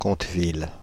Conteville (French pronunciation: [kɔ̃tvil]
Fr-Paris--Conteville.ogg.mp3